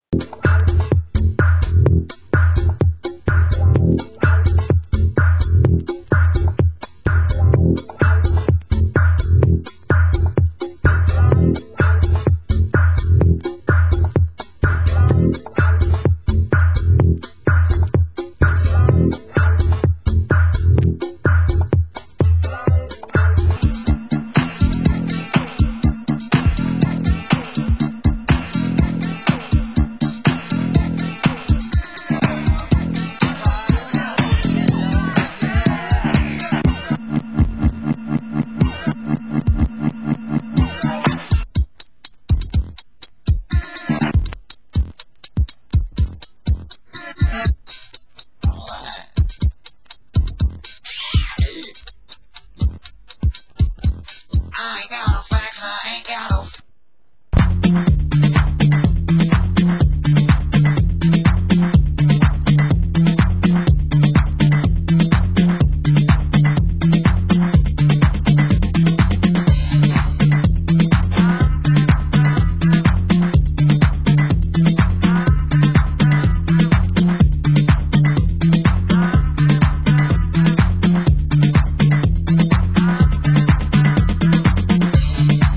Genre Tech House